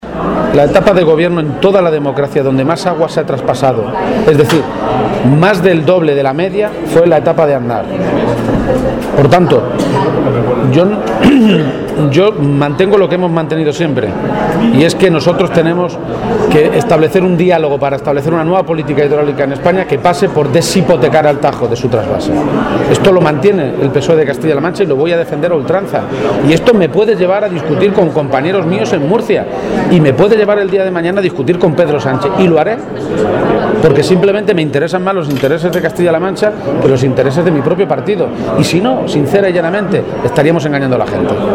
El candidato socialista a la Presidencia de Castilla-La Mancha participó anoche en la cena solidaria del PSOE de Guadalajara
García-Page hizo estas declaraciones durante la cena de Navidad del PSOE de esta provincia, a la que asistieron cerca de 400 personas militantes y simpatizantes, y que nuevamente tuvo un carácter solidario con la recogida de juguetes que serán entregados a familias con dificultades económicas.